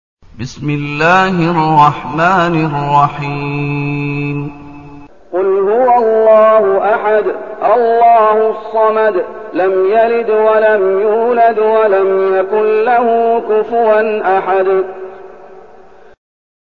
المكان: المسجد النبوي الشيخ: فضيلة الشيخ محمد أيوب فضيلة الشيخ محمد أيوب الإخلاص